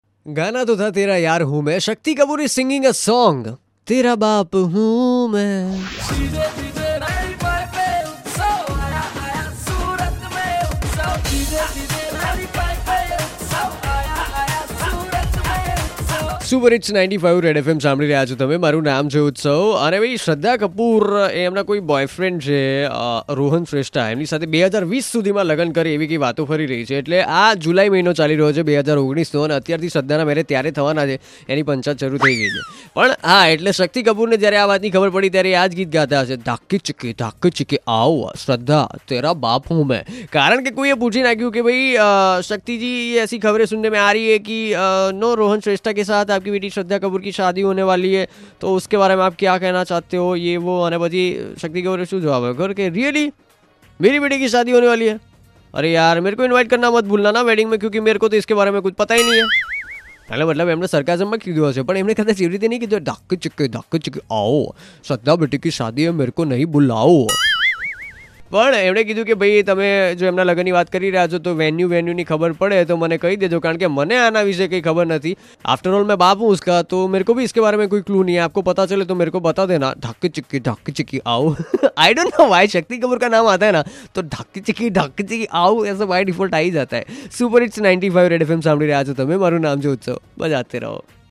Shraddha Kapoor Marrying Rohan Shrestha In 2020? 'No Clue, Invite Me Too,' Says Dad Shakti Kapoor, shakti mimicry